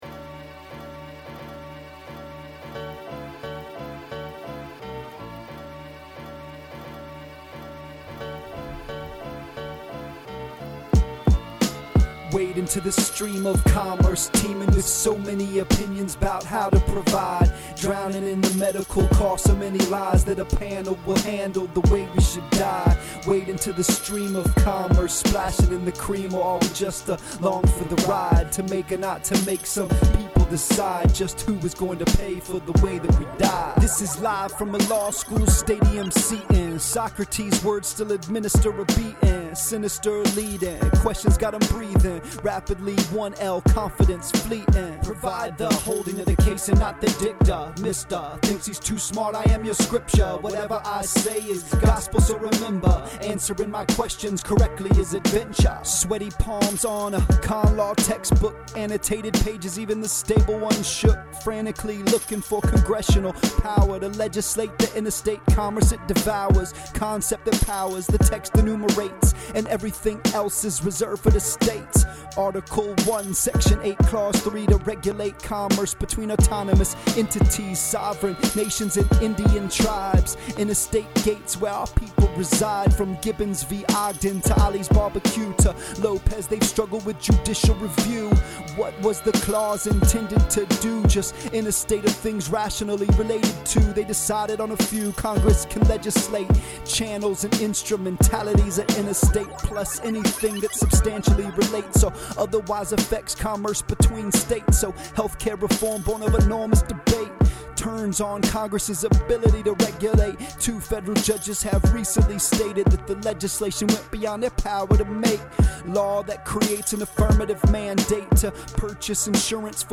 Like Dude, he wrote a RAP about THE COMMERCE CLAUSE.